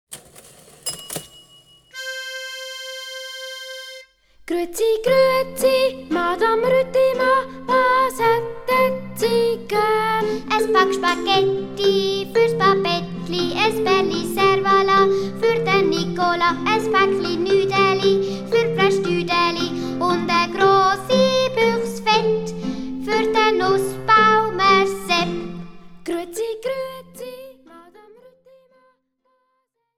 Besetzung: Gesang